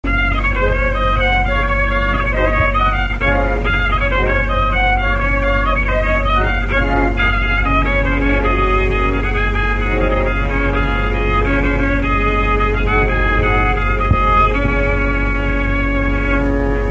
branle : courante, maraîchine
Pièce musicale inédite